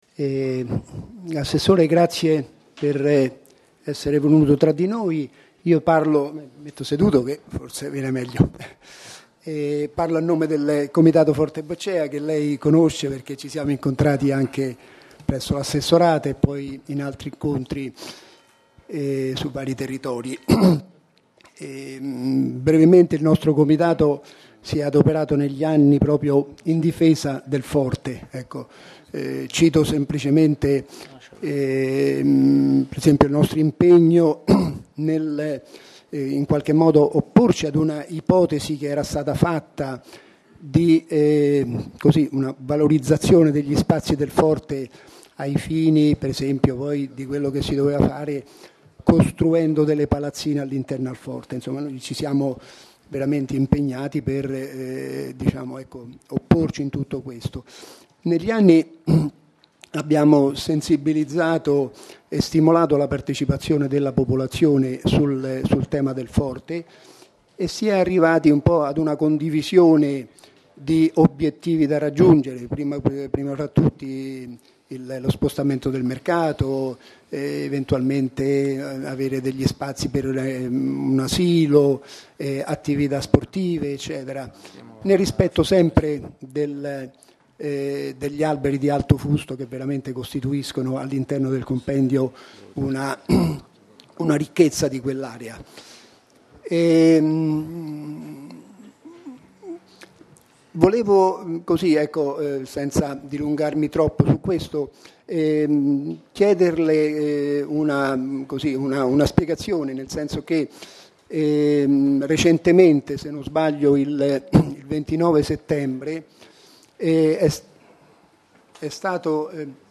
Registrazione integrale dell'incontro svoltosi il 16 ottobre 2014 presso la sala consiliare del Municipio XIII, in Via Aurelia, 474.